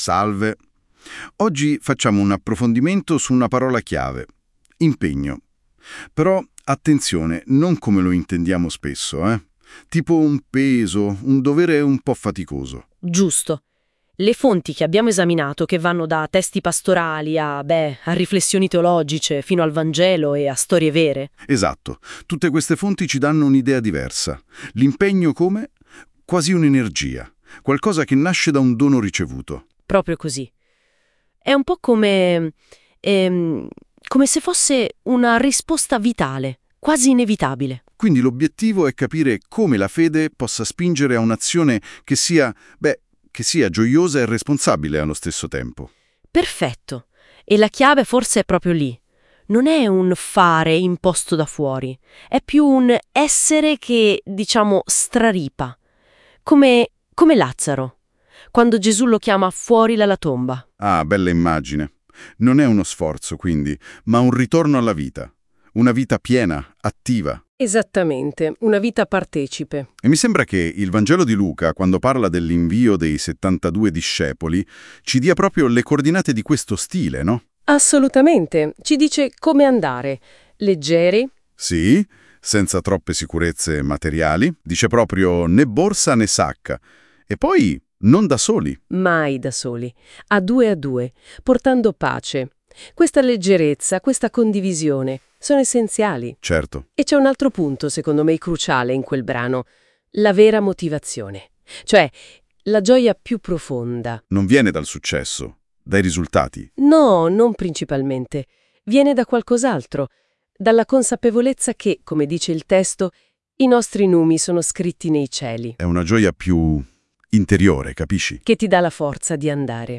Essi - sulla base del materiale presentato - elaborano i contenuti stessi in forma accattivante: podcast e video sono elaborati dall'AI "NOTEBOOKLM".